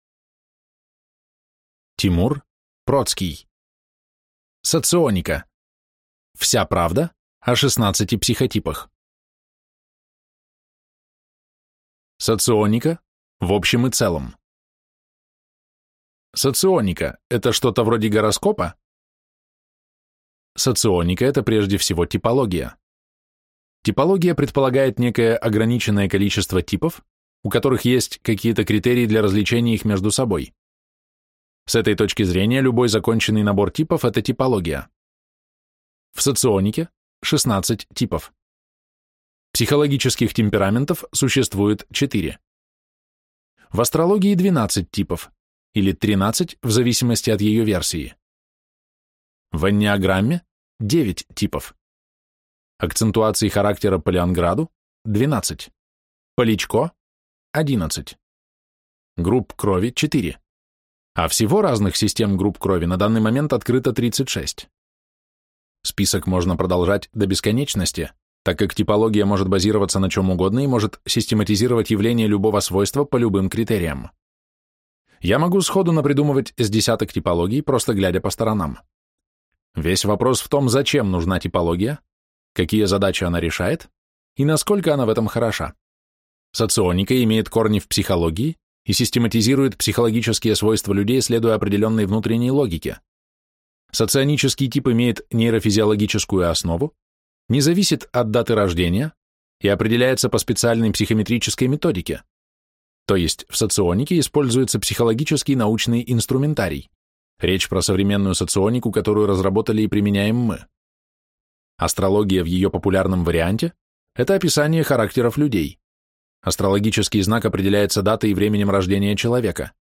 Аудиокнига Соционика. Вся правда о 16 психотипах | Библиотека аудиокниг